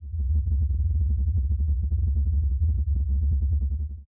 ambient_drone_5.ogg